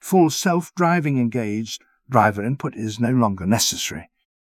full-self-driving-engaged.wav